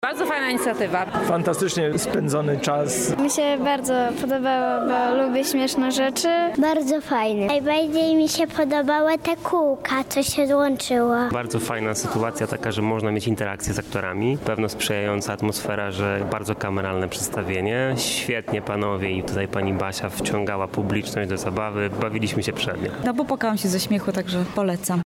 Festiwal Improwizacji Teatralnych, relacja